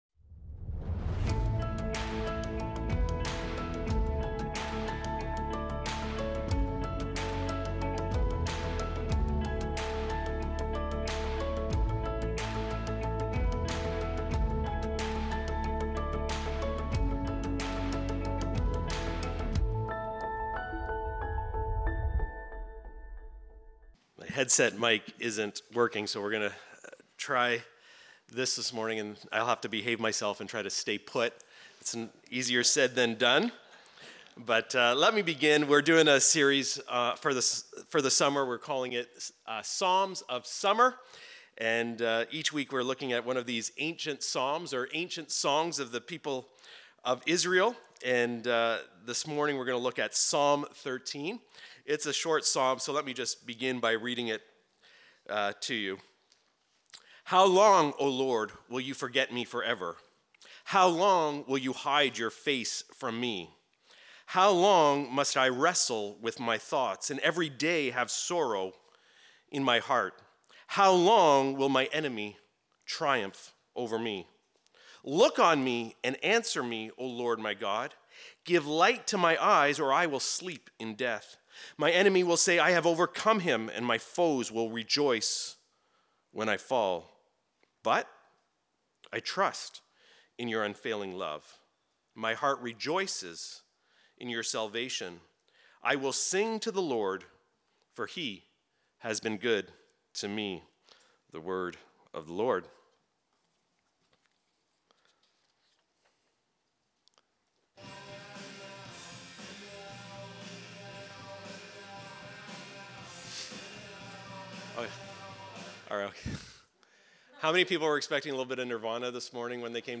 Recorded Sunday, August 11, 2025, at Trentside Bobcaygeon.